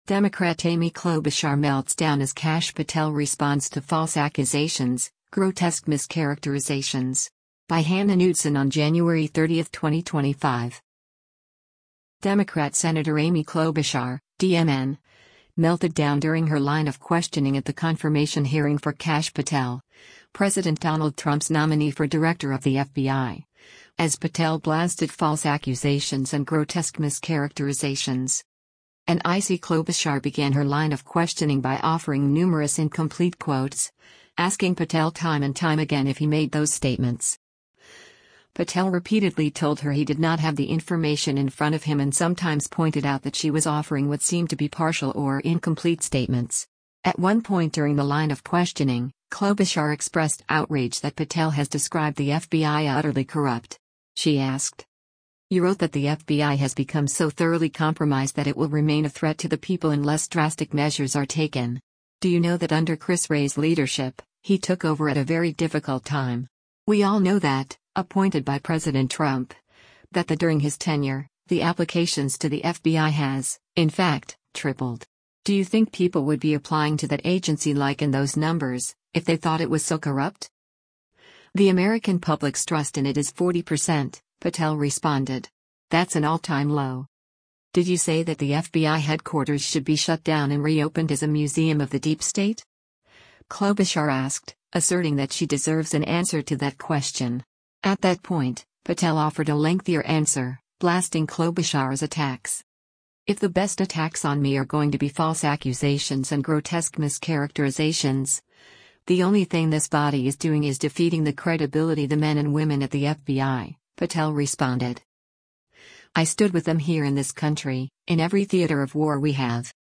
Sen. Amy Klobuchar, D-Minn., speaks during a confirmation hearing before the Senate Judici
At that point, Klobuchar became even more visibly and audibly irritated.